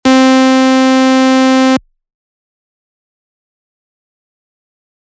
To help understand the envelope better, I’ve created a few examples with a simple saw wave:
• The second has a fast attack as well, but with a medium sustain and decay. No release.